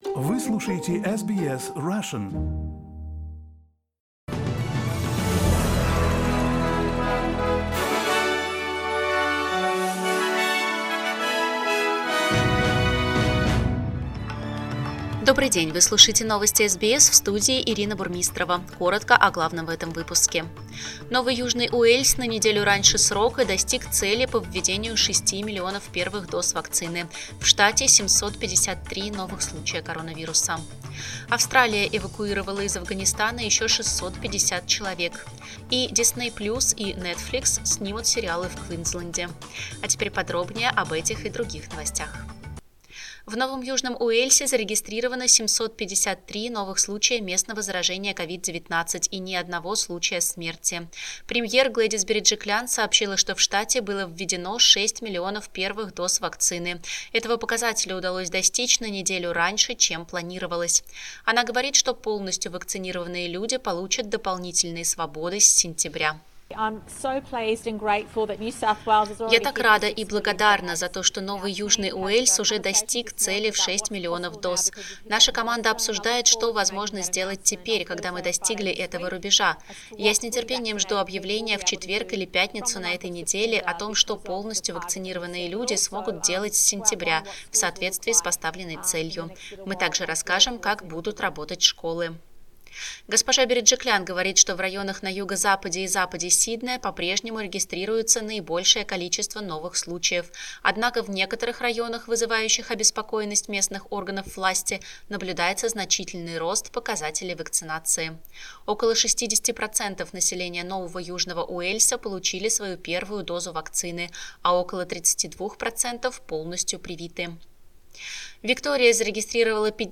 SBS news in Russian - 24.08